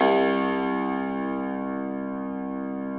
53k-pno02-C0.wav